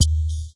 描述："electro bass drum" techno electronic airy simple tight but with a mono variation.
Tag: 通风 电低音鼓 电子 简单 TECHNO